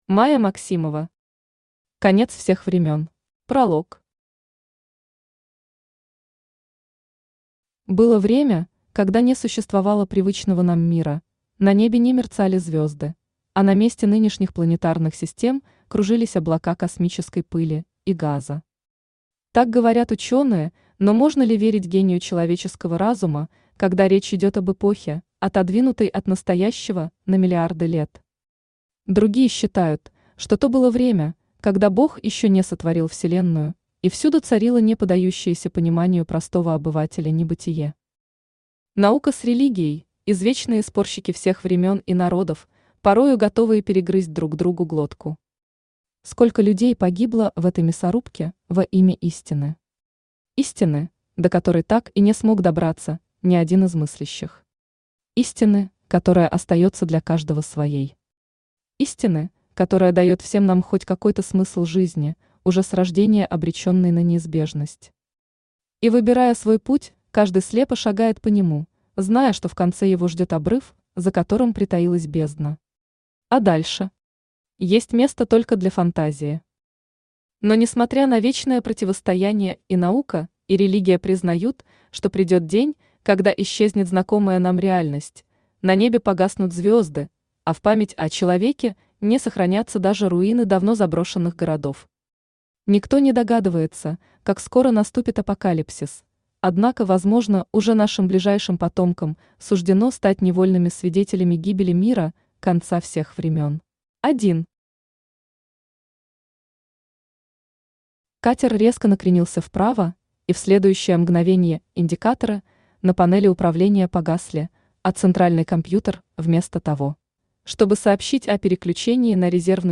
Аудиокнига Конец всех времён | Библиотека аудиокниг
Aудиокнига Конец всех времён Автор Майя Максимова Читает аудиокнигу Авточтец ЛитРес.